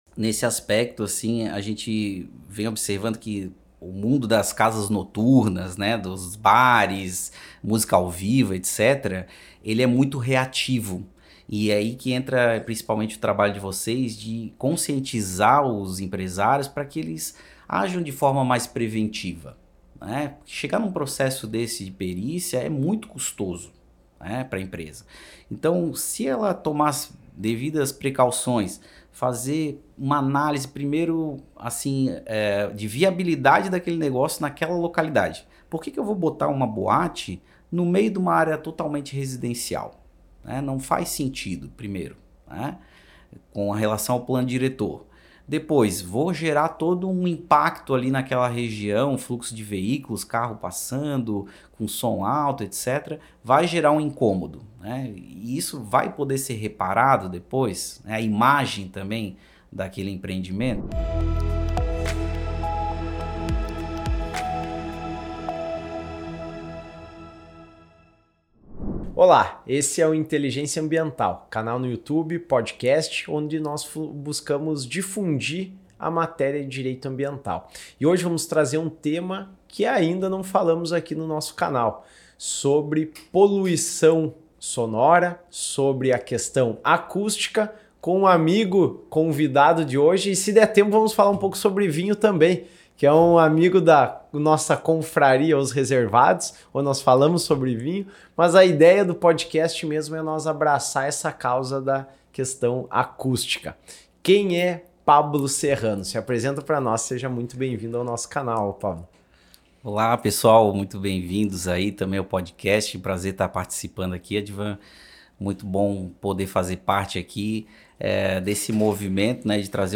uma conversa profunda sobre os desafios da poluição sonora em áreas residenciais e o envolvimento do direito ambiental neste meio.